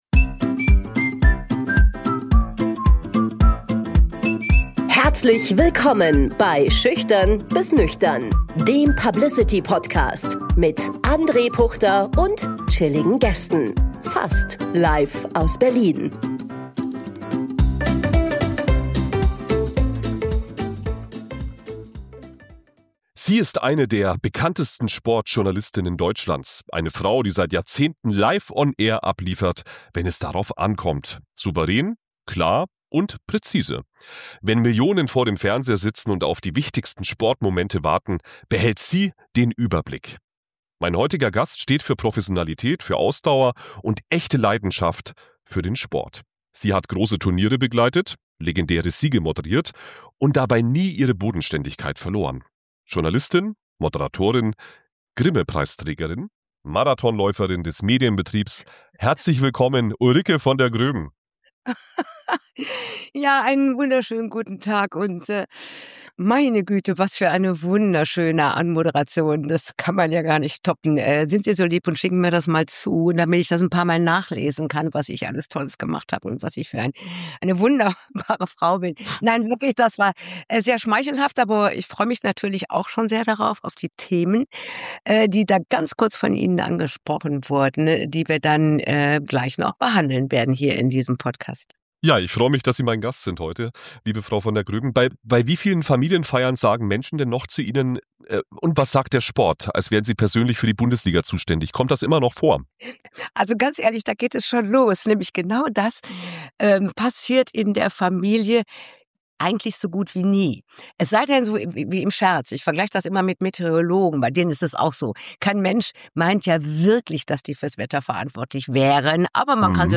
Wie bleibt man glaubwürdig in einer Branche, die immer schneller, lauter und meinungsstärker wird? Und was bedeutet journalistische Haltung heute? Ein Gespräch über Verlässlichkeit, Professionalität und die Kunst, ruhig zu bleiben, wenn Geschichte passiert.